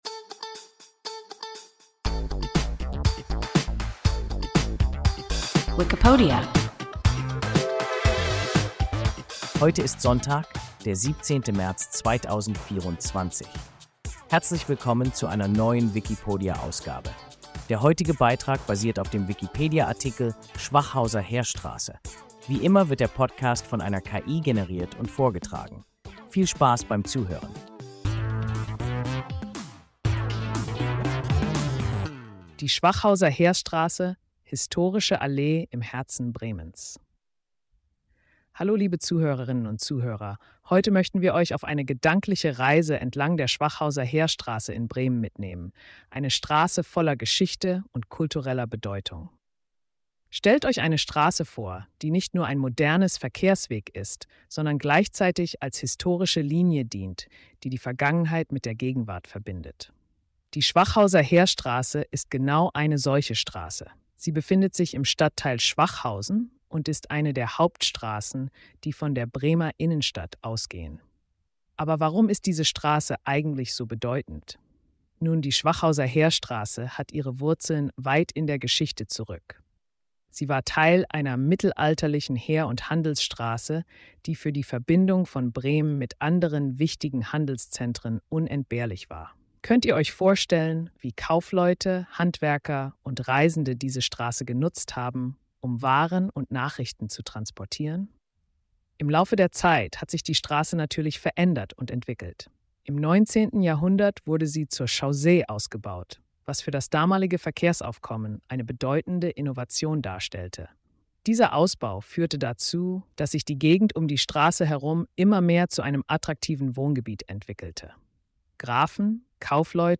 Schwachhauser Heerstraße – WIKIPODIA – ein KI Podcast